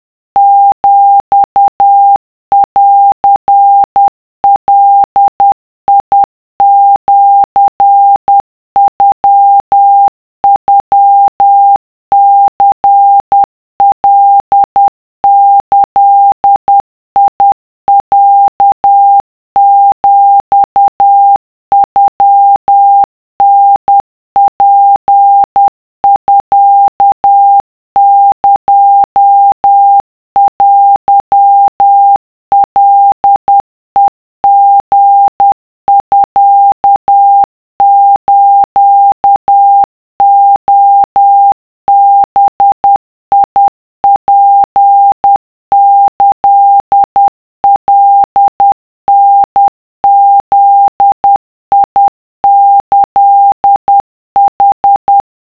【cw】2【wav】 / 〓古文で和文系〓
とりあえず50CPM (=10WPM)で作ってみた